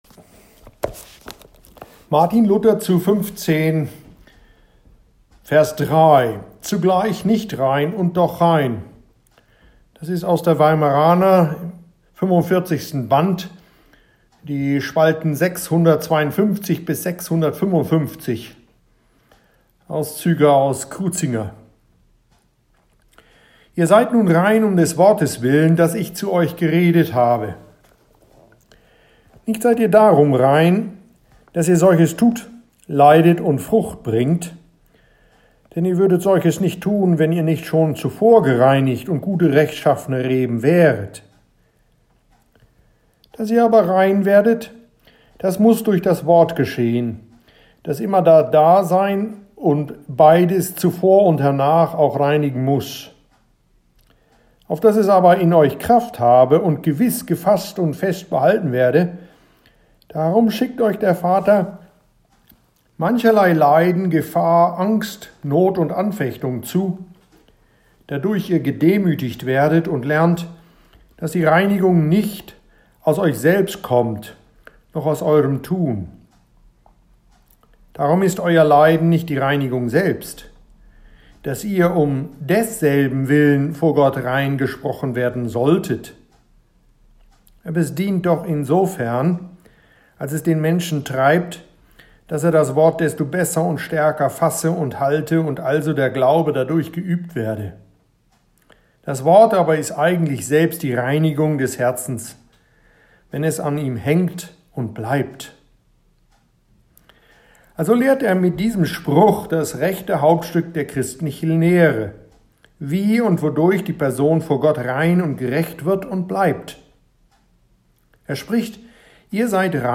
Dr. Martin Luther comments this vers – and here´s some of that audible in German – readings of John 15,3 in “Luthers Evangelien-Auslegung 4.Teil.” ed. Eduard Ellwein.